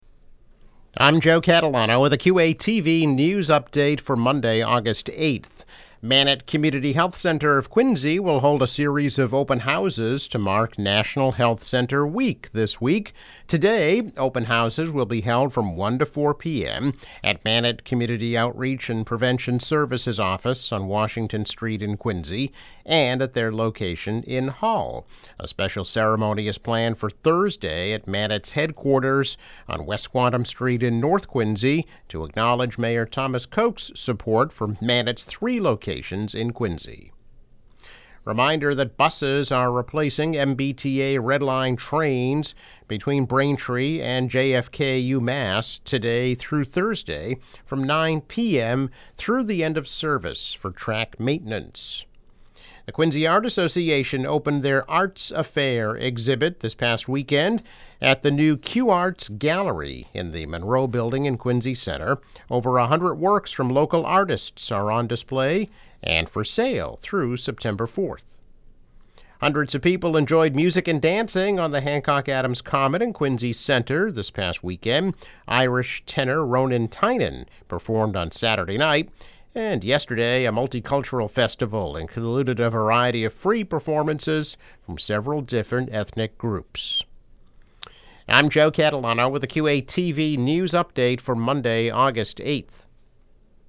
News Update - August 8, 2022